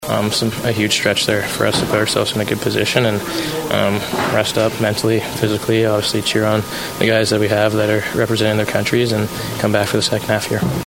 Wild captain Jared Spurgeon on the play of his team heading into the break.